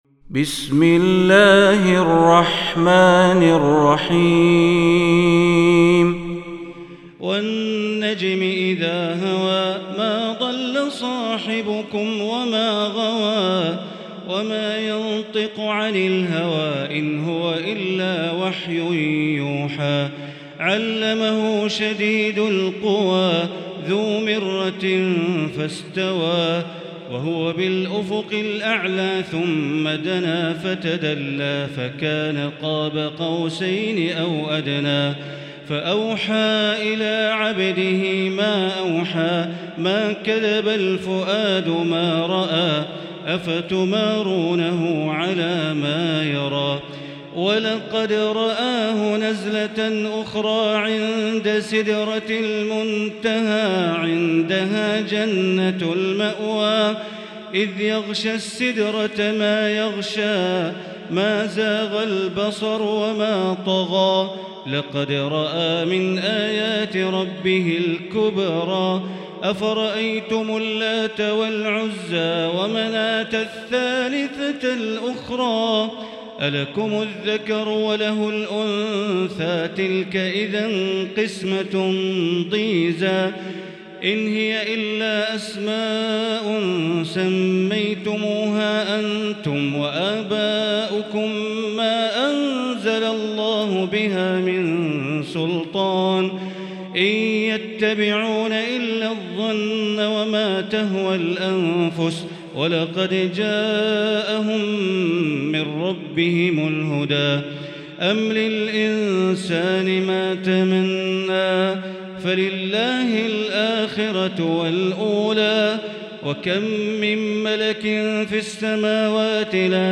المكان: المسجد الحرام الشيخ: معالي الشيخ أ.د. بندر بليلة معالي الشيخ أ.د. بندر بليلة النجم